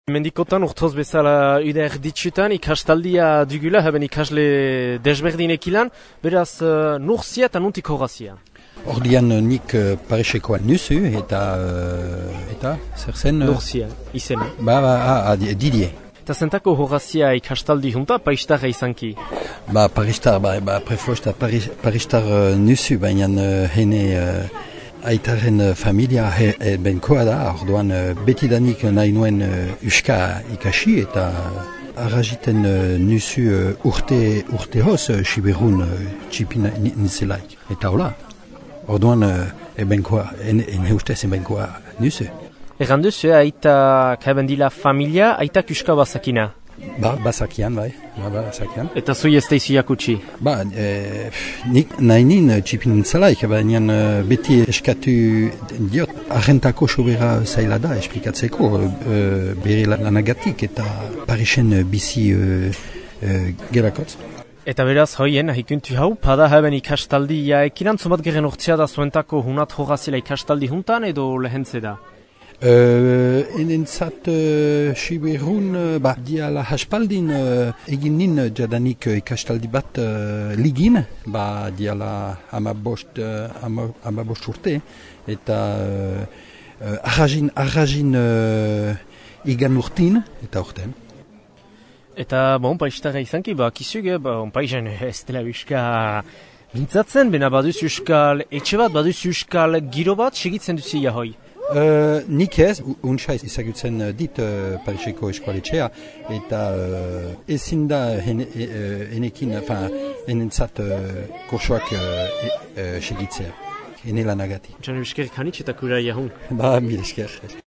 Entzün ikasleetan den Parisetar bat :
ikastaldia2012paristarbat.mp3